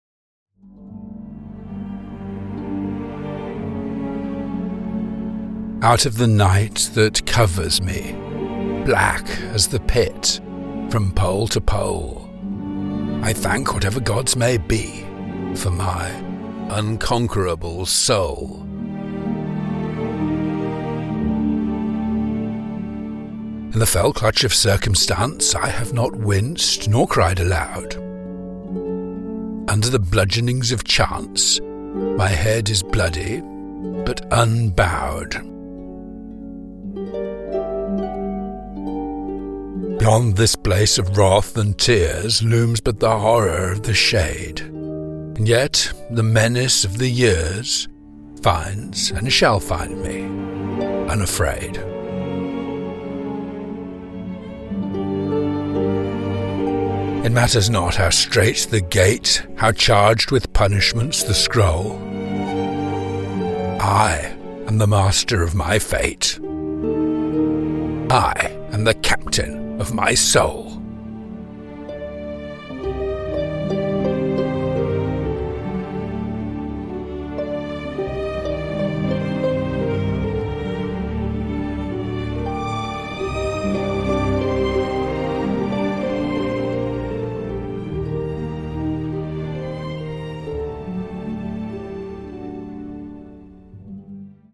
British Voiceover for Poetry Narration: